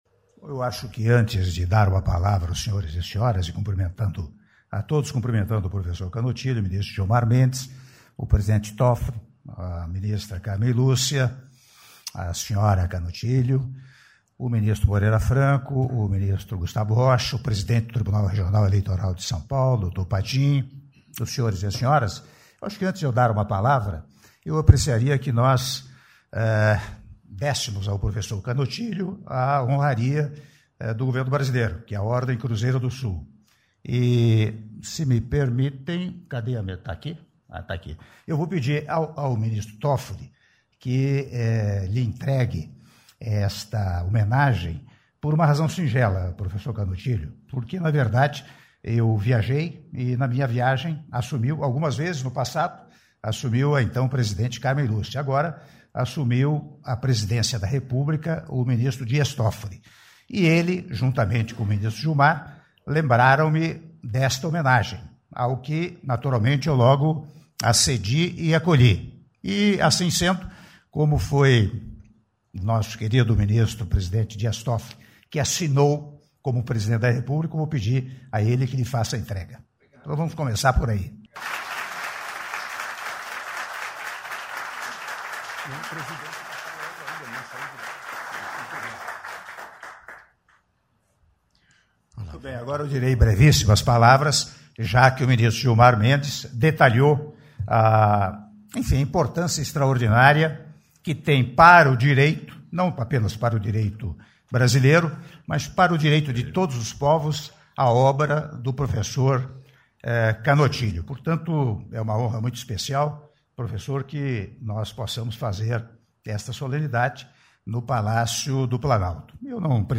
Áudio do Discurso do Presidente da República, Michel Temer, durante a solenidade de entrega da Ordem Nacional do Cruzeiro do Sul, no Grau Comendador, ao senhor José Gomes Canotilho- Brasília/DF-(04min34s)